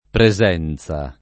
pre@$nZa] s. f. — ant. presenzia [pre@$nZLa]: alla presenzia di messer lo papa [alla pre@$nZLa di meSS$r lo p#pa] (Brunetto); la sua dolcissima presenzia [la Sua dol©&SSima pre@$nZLa] (Magnifico)